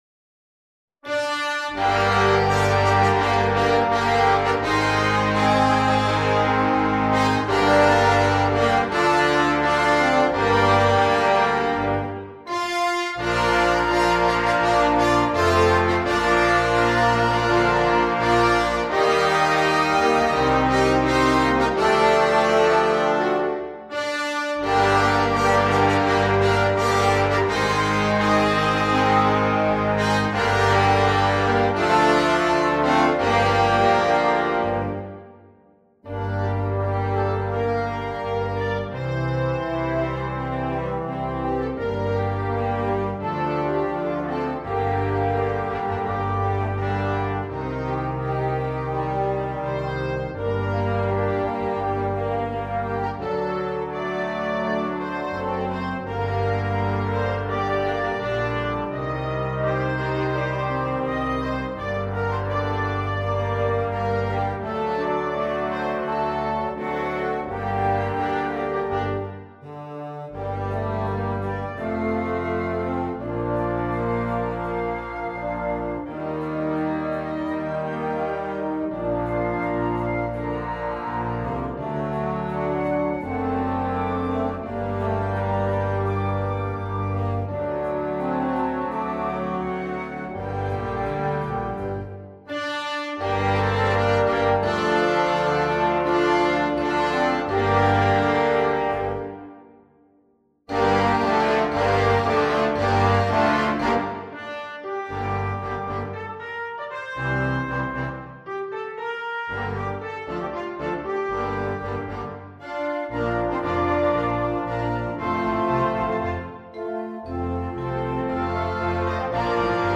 2. Blasorchester
Noten für Blasorchester
komplette Besetzung
Unterhaltung